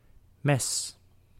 Ääntäminen
Ääntäminen : IPA: [ˈmɛs] Haettu sana löytyi näillä lähdekielillä: hollanti Käännös Ääninäyte Substantiivit 1. knife US UK 2. cleaver 3. razor Suku: n .